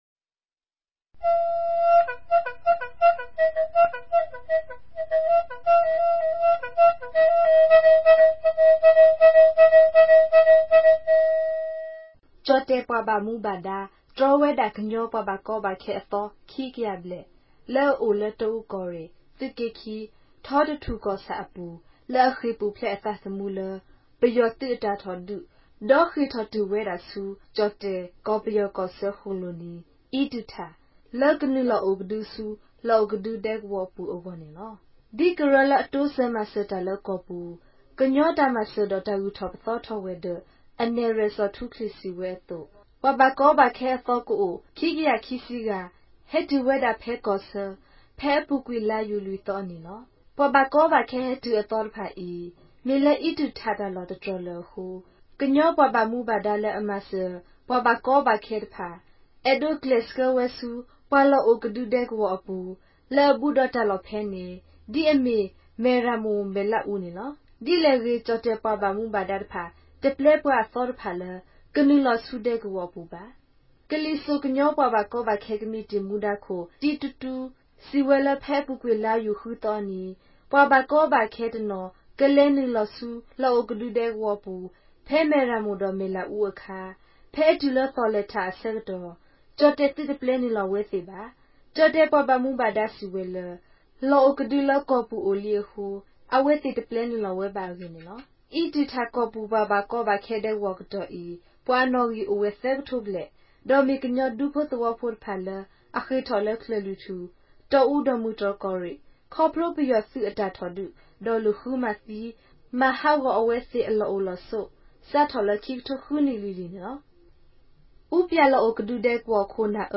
ကရင်ဘာသာ အသံလြင့်အစီအစဉ်မဵား